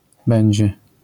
Pronunciation of ę depending on its position
ę + ś, ź, ć, , si, zi, ci, dzi /ɛɲ/ [ɛj̃]
będzie ('it will be') /ˈbɛɲd͡ʑɛ/ [ˈbɛj̃d͡ʑɛ]